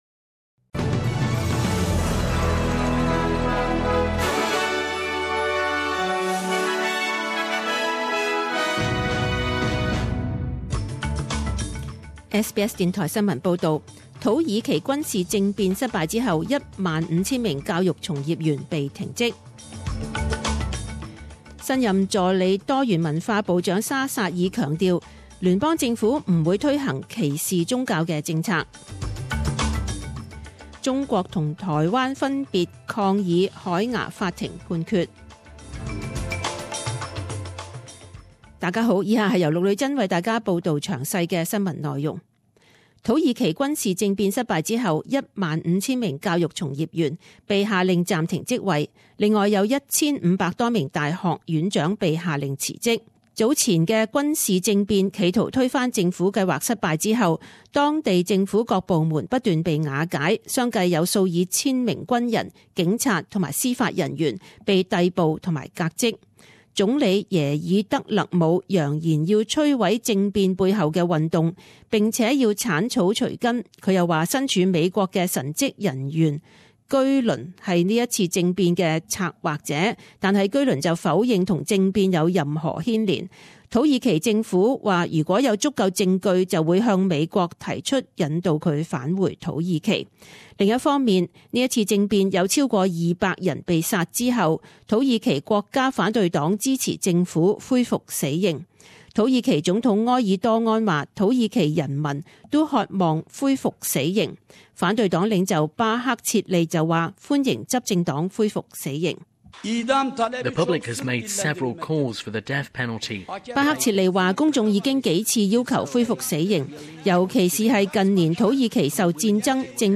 十點鐘新聞報導 （七月二十日）